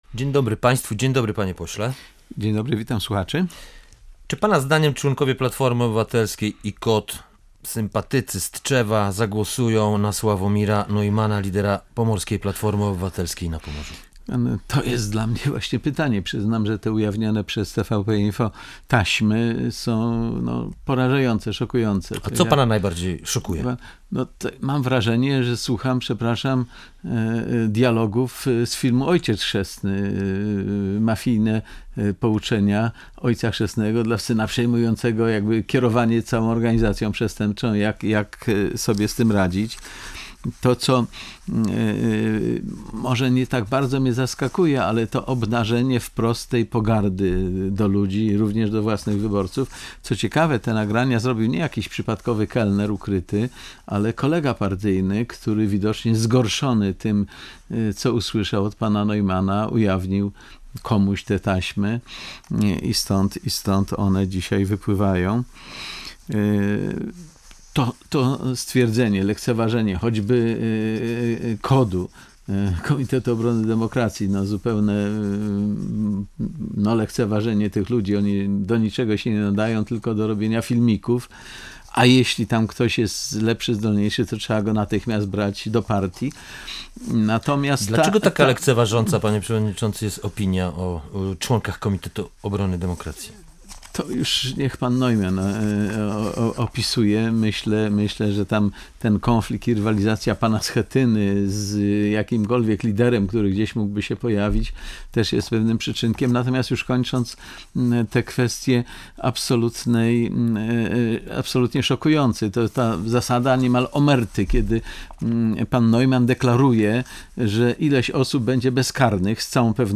Gościem Dnia Radia Gdańsk był poseł Prawa i Sprawiedliwości Janusz Śniadek.